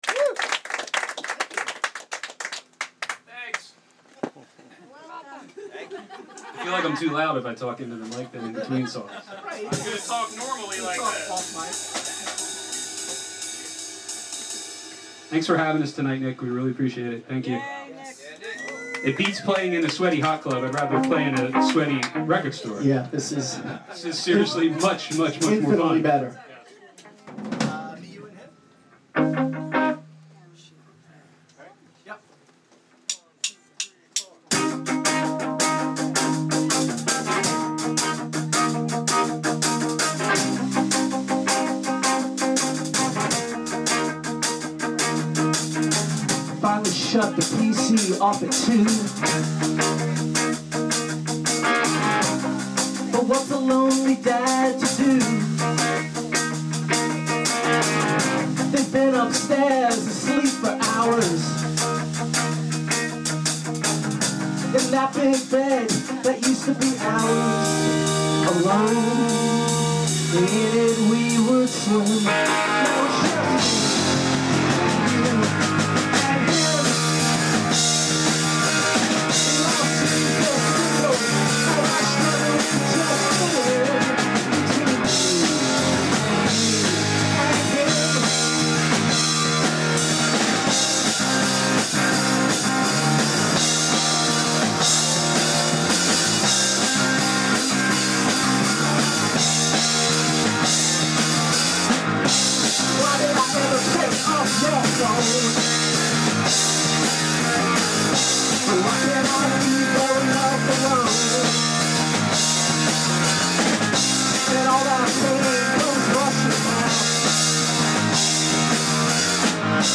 record release party